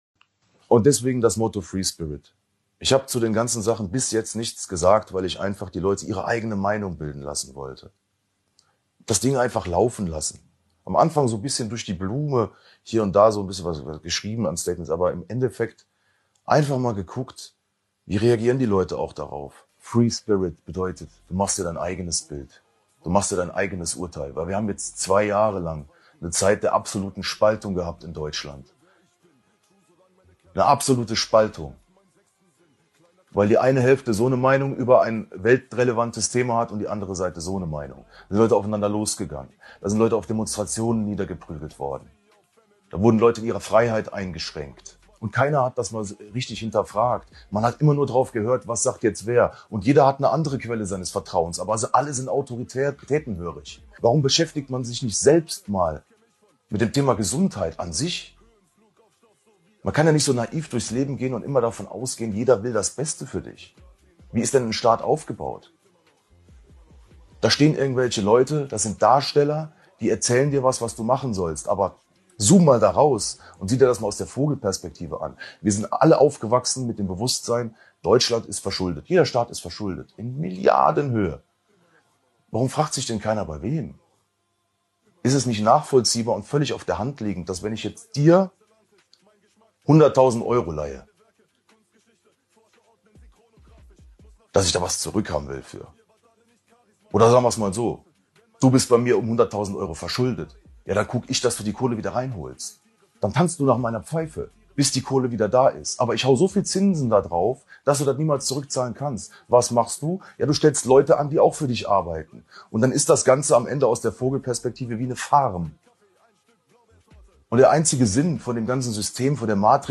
Kollegah alias Felix Blume in einem ehrlichen und direktem Interview. Warum beschäftigt man sich selbst nicht mal mit dem Thema Gesundheit an sich?